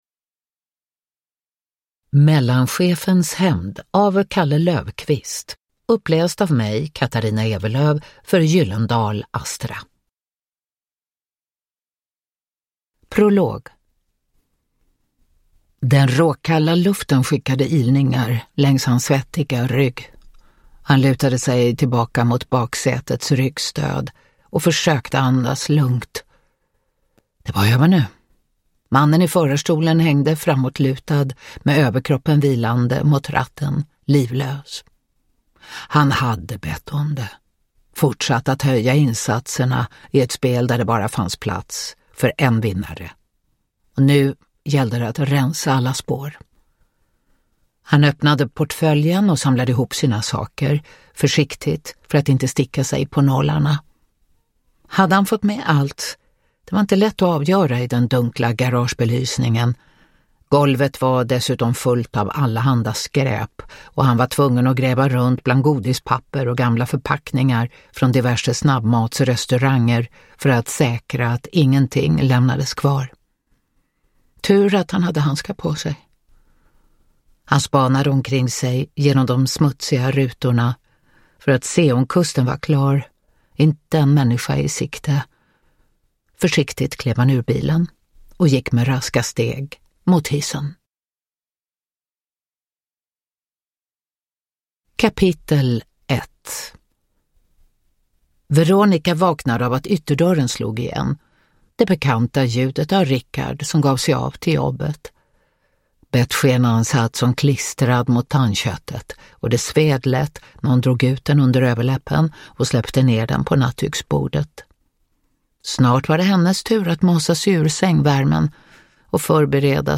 Mellanchefens hämnd – Ljudbok
Uppläsare: Katarina Ewerlöf